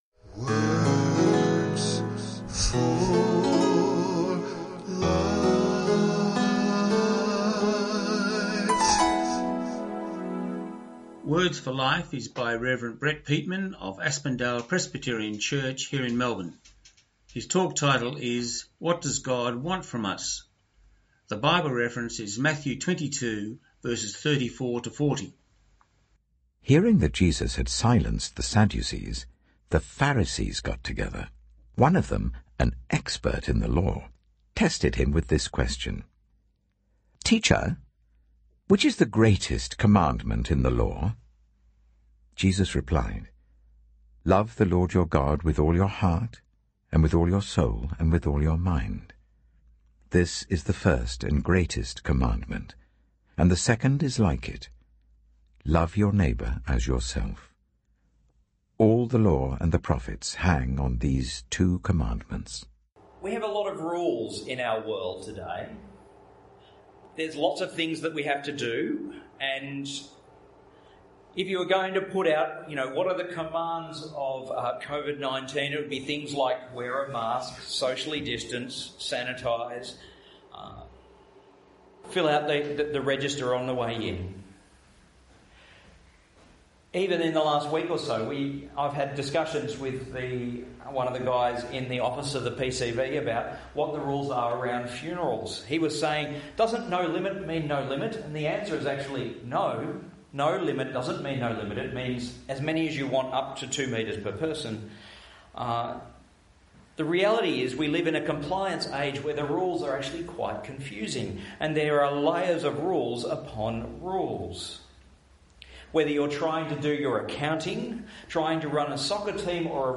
Christian audio recordings from a radio program in Australia